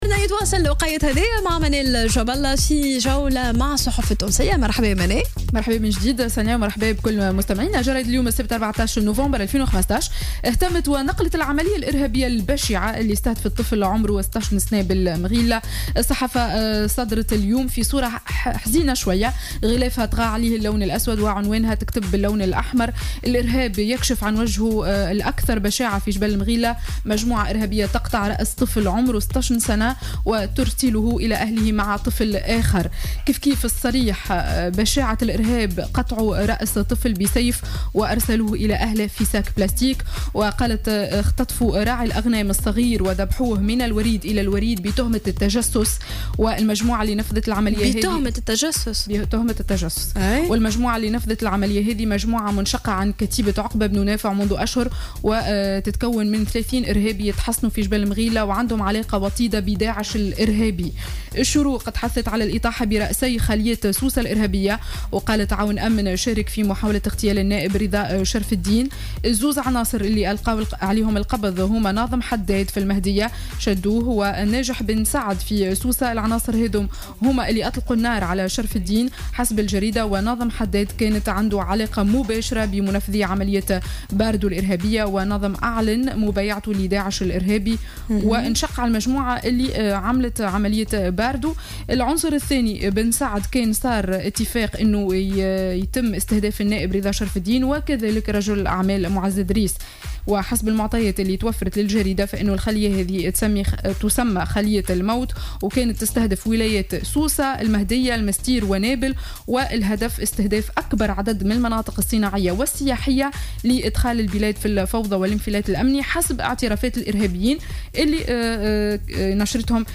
Revue de presse du Samedi 14 Novembre 2015